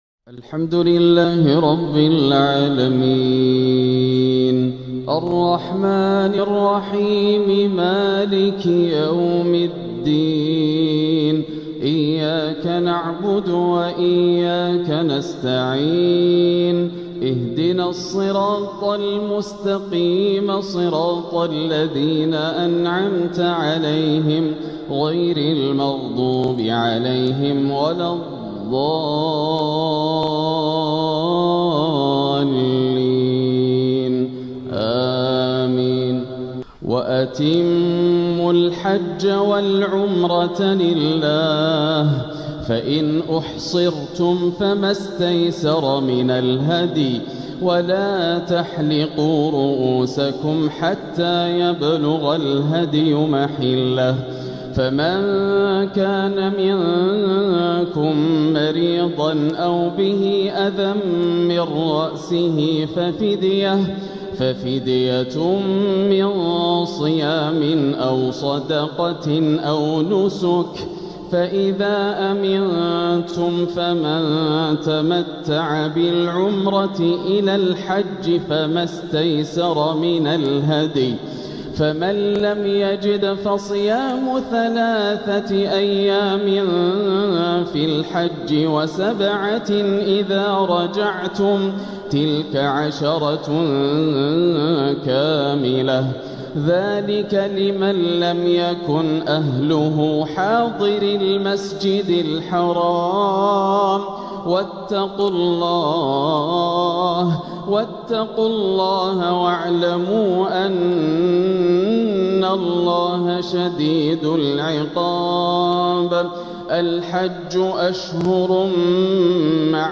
العقد الآسر لتلاوات الشيخ ياسر الدوسري تلاوات شهر ذو الحجة عام ١٤٤٦هـ من الحرم المكي > سلسلة العقد الآسر من تلاوات الشيخ ياسر > المزيد - تلاوات ياسر الدوسري